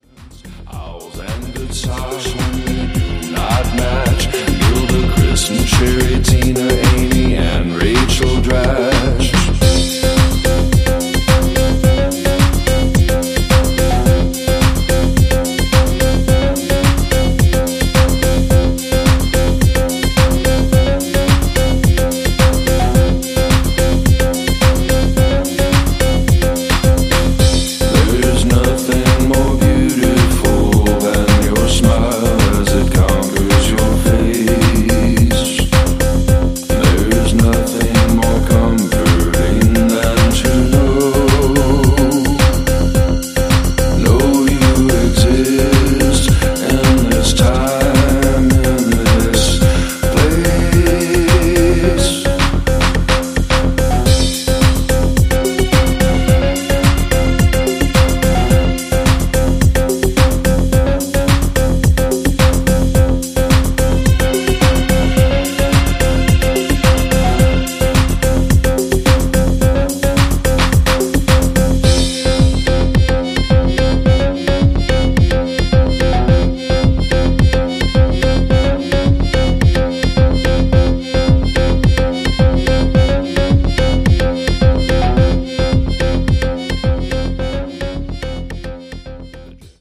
ダークなムードとルーディーなグルーヴでズブズブと怪しく引き込んでいく、なんとも痺れる仕上がりとなっています。